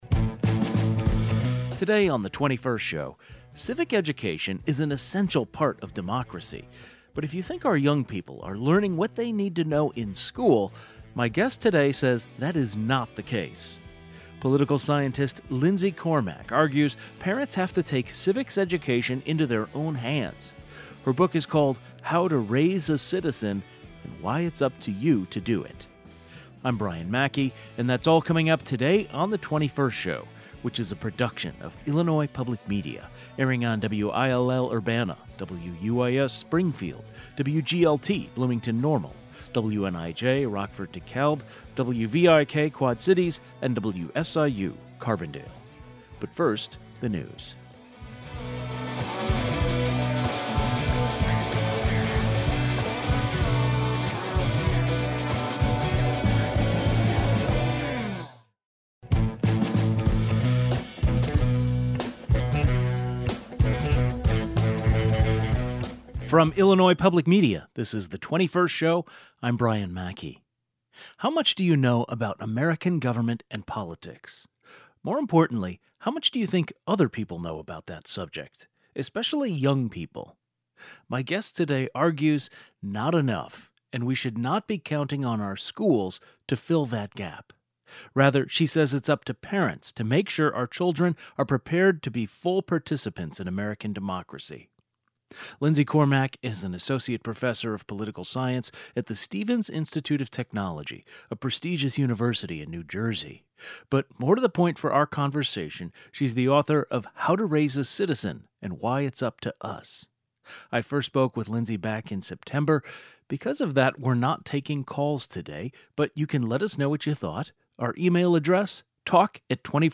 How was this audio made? Today's show included a rebroadcast of the following "best of" segment, first aired September 15, 2025: Book makes the case civic education should come from parents more than school.